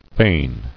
[fain]